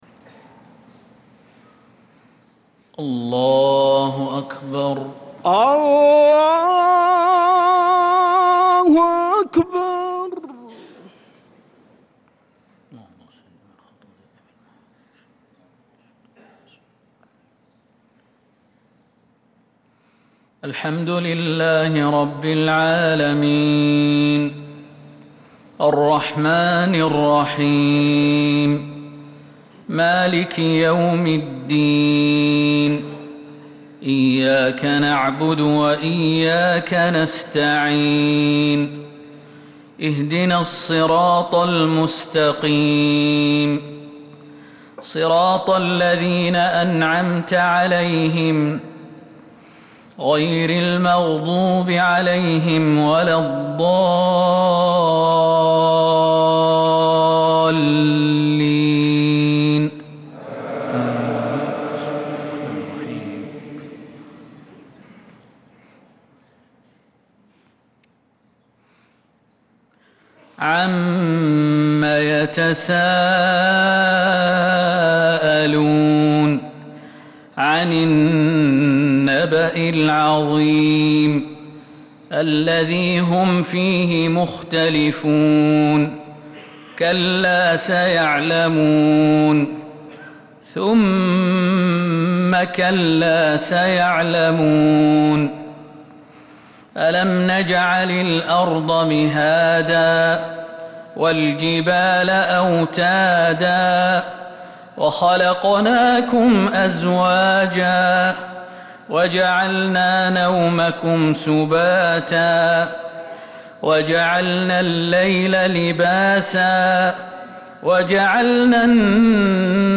فجر الأربعاء 14 محرم 1447هـ سورة النبأ كاملة | Fajr prayer from Surah An-Naba 9-7-2025 > 1447 🕌 > الفروض - تلاوات الحرمين